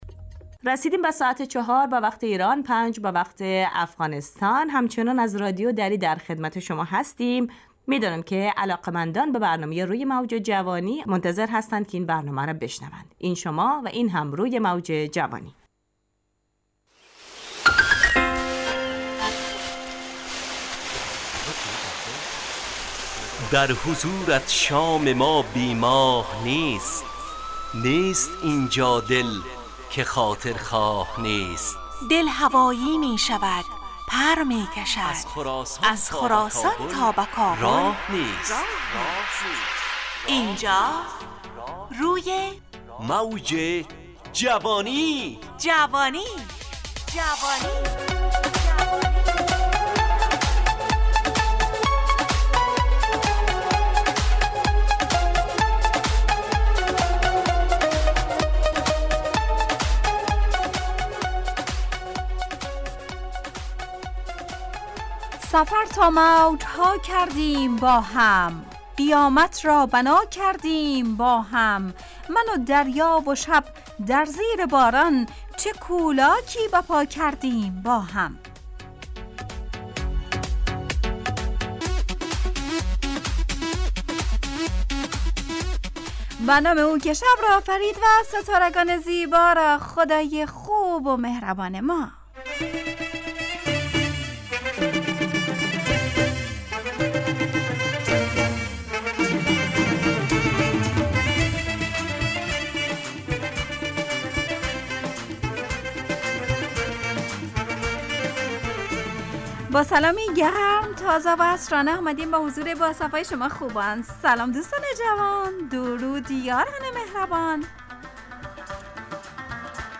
از شنبه تا پنجشنبه ازساعت 17 الی 17:55 به وقت افغانستان، طرح موضوعات روز، وآگاهی دهی برای جوانان، و.....بخشهای روزانه جوان پسند. همراه با ترانه و موسیقی مدت برنامه 55 دقیقه .